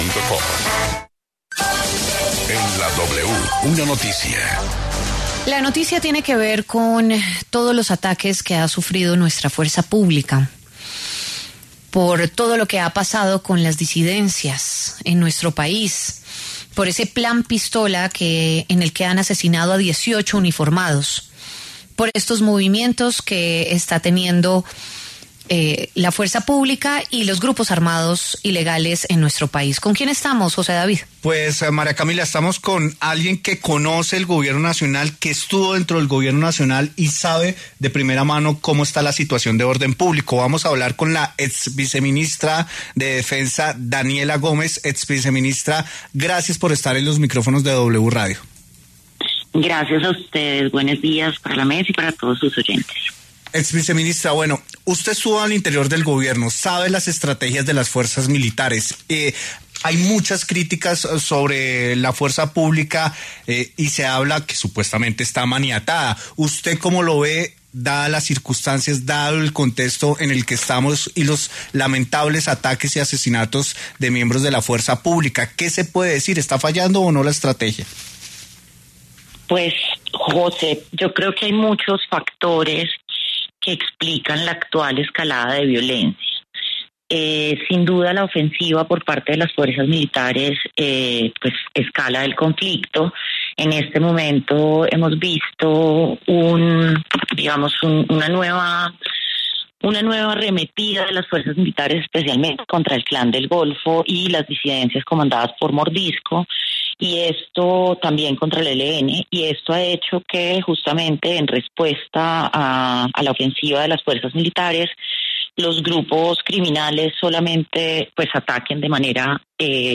Daniela Gómez, ex viceministra de Defensa, conversó con La W sobre la situación de orden público y la escalada de violencia que vive el país.